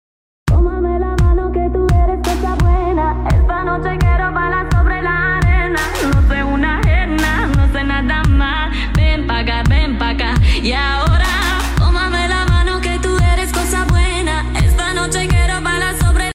M25 TWS True Wireless Bluetooth sound effects free download